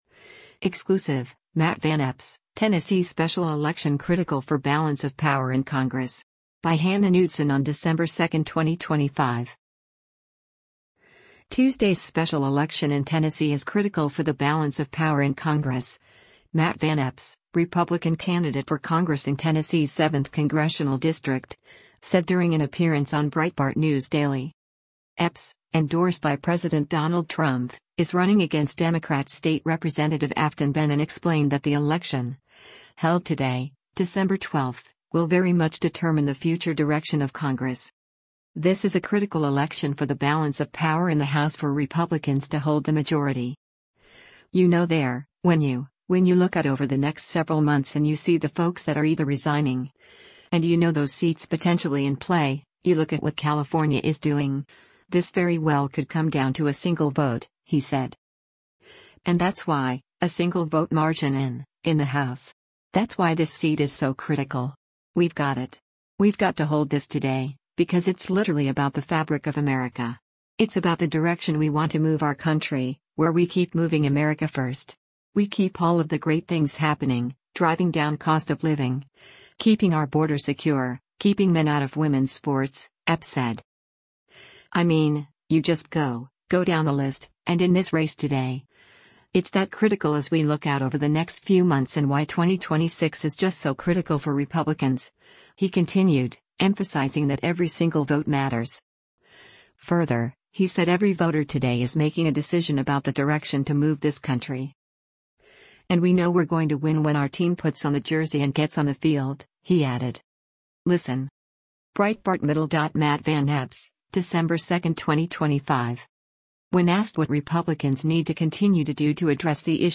Breitbart News Daily airs on SiriusXM Patriot 125 from 6:00 a.m. to 9:00 a.m. Eastern.